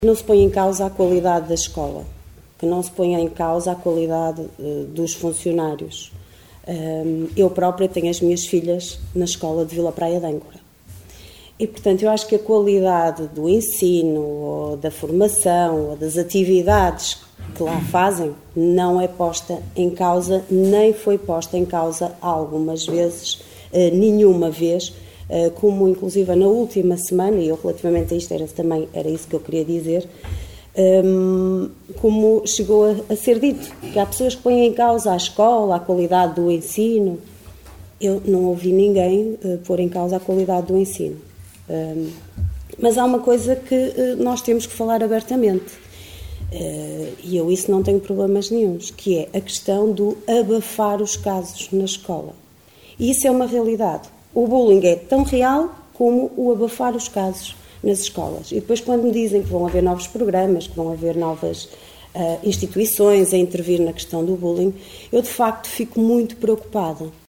Liliana Silva, da Coligação O Concelho em Primeiro (OCP), acusou na última reunião do executivo a direção do Agrupamento de Escolas de Caminha de abafar os casos de bullying (e outros).